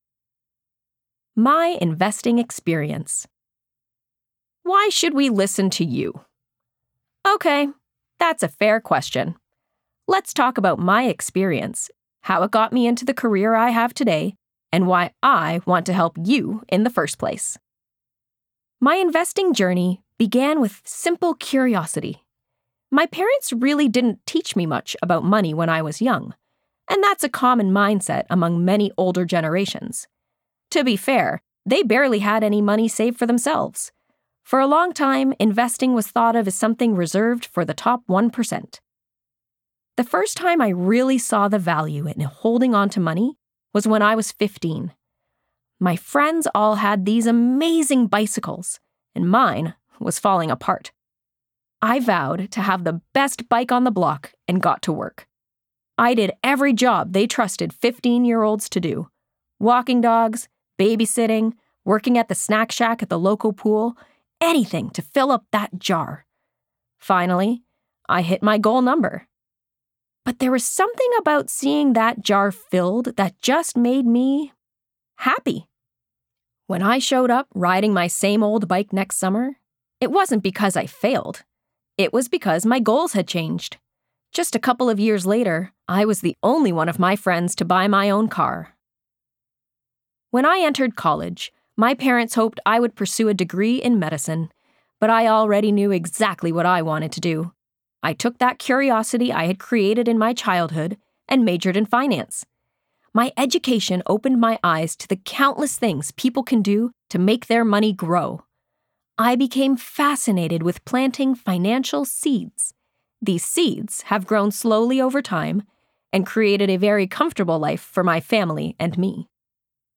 Audiobook Narration: Investing for Teenagers
English - USA and Canada